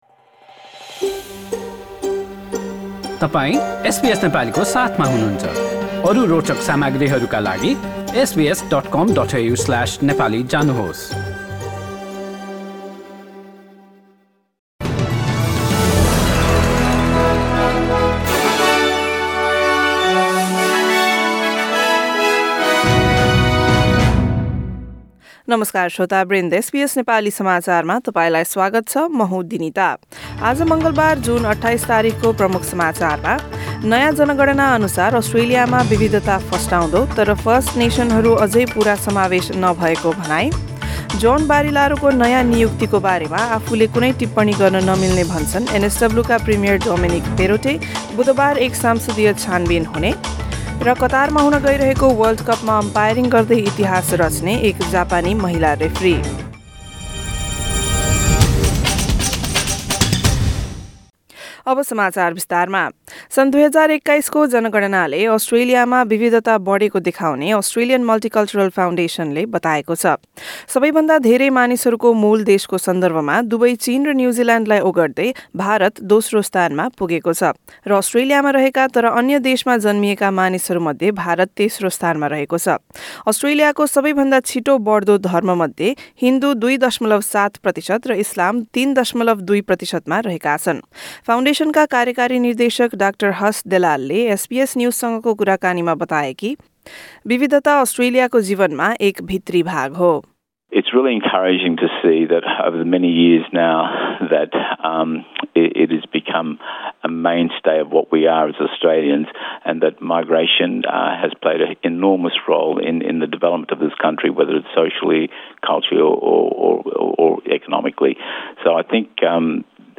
एसबीएस नेपाली अस्ट्रेलिया समाचार: मङ्गलबार २८ जुन २०२२